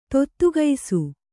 ♪ tottugaisu